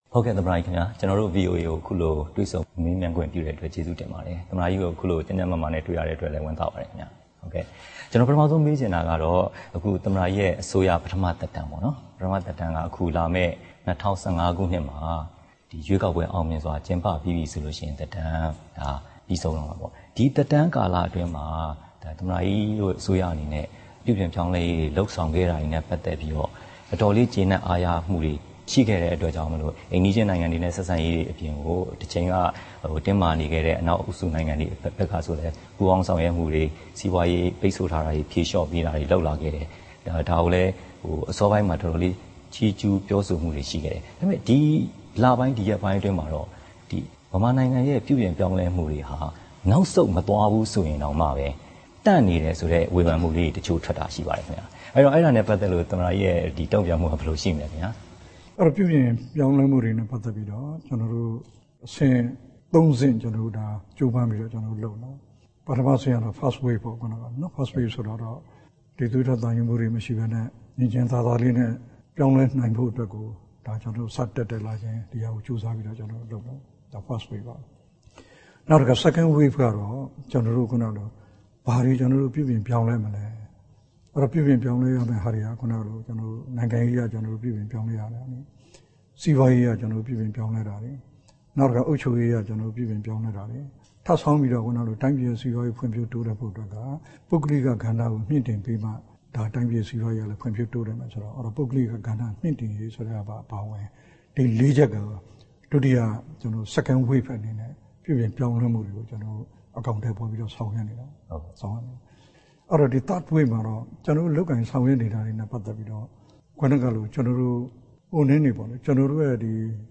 ဦးသိန်းစိန်နဲ့ တွေ့ဆုံမေးမြန်းခန်း